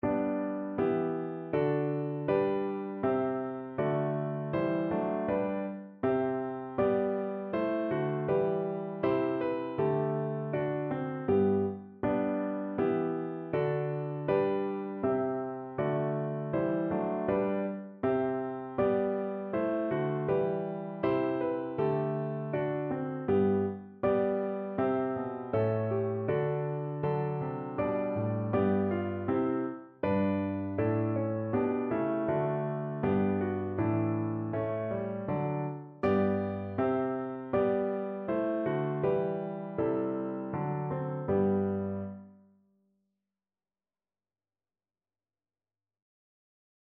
Notensatz 1 (4 Stimmen gemischt)
• gemischter Chor mit Akk. [MP3] 733 KB Download